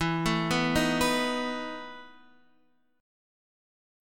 EM7sus4 chord